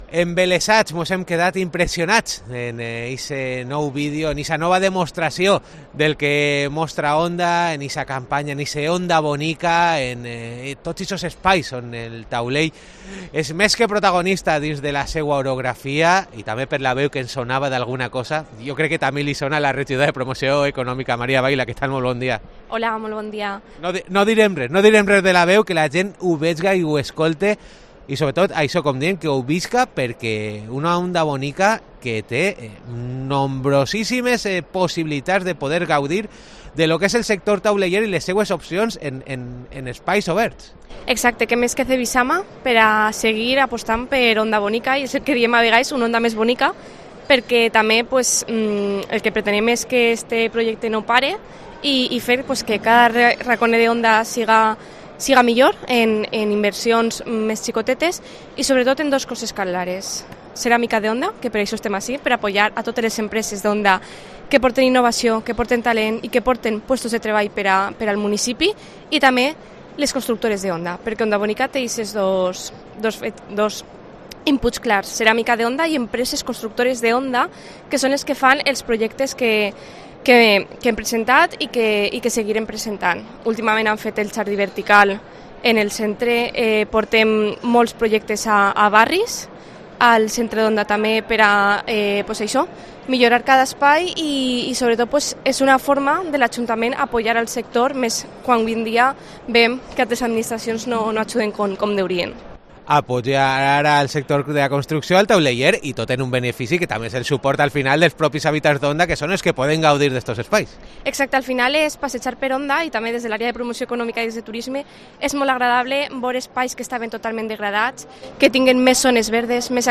Entrevista
'Onda Bonica' muestra en Cevisama la mejora en espacios públicos , como explica la concejala de Promoción Económica del Ayuntamiento de Onda, María Baila